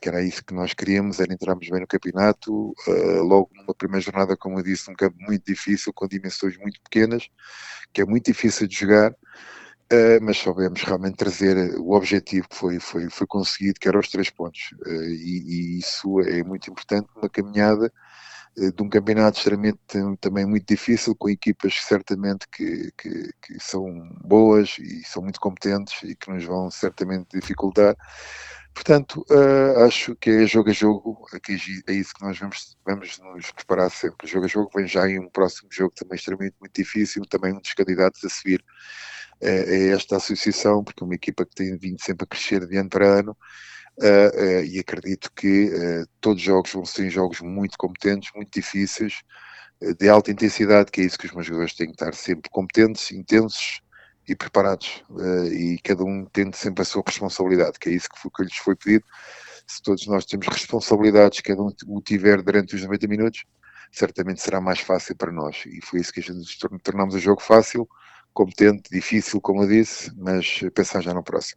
O técnico fez a análise do jogo: